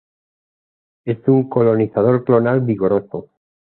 /koloniθaˈdoɾ/